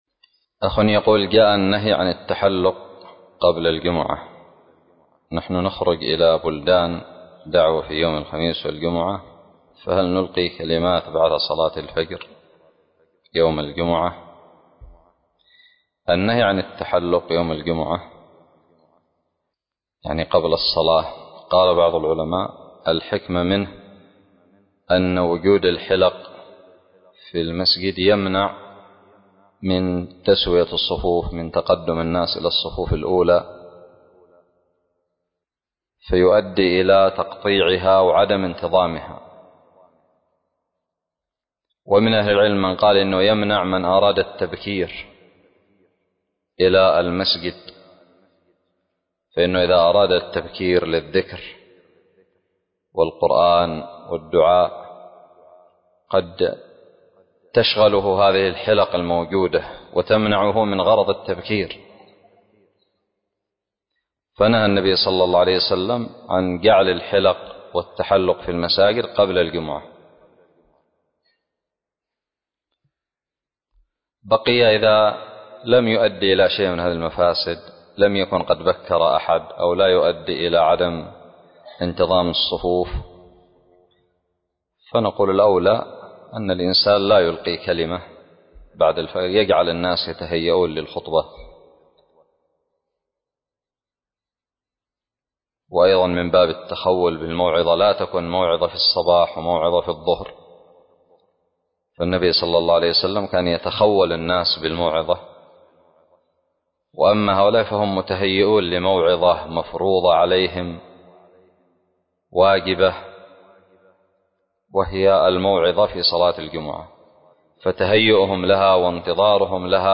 فتاوى عامة
سؤال قدم لفضيلة الشيخ حفظه الله